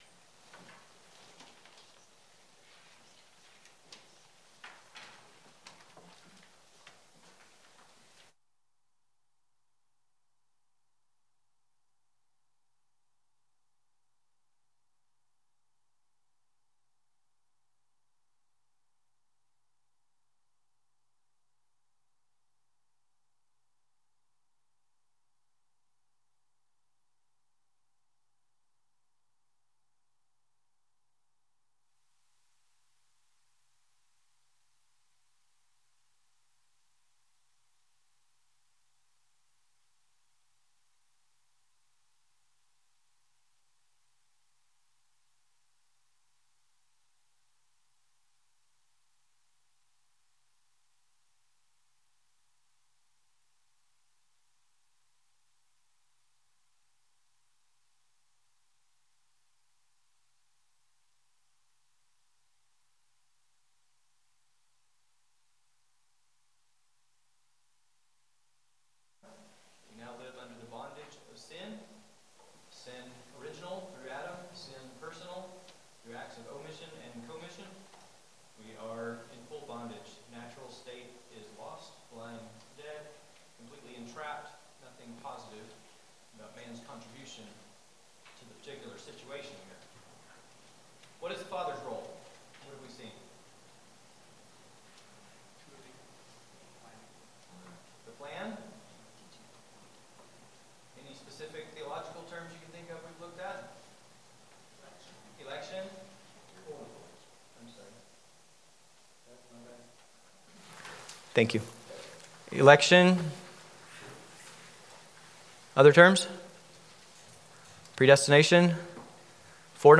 Passage: John 3:1-21 Service Type: Sunday School « Evening Hymn Sing The Way of Salvation